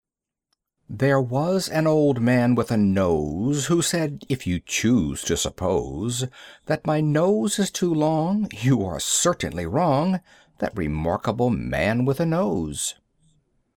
There Was an Old Man with a Nose Limerick There was an Old Man with a nose, Who said, 'If you choose to suppose, That my nose is too long, You are certainly wrong!'